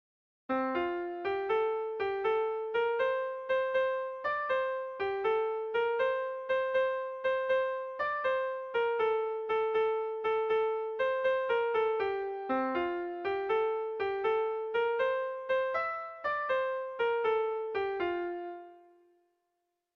Bertso melodies - View details   To know more about this section
ABDEAF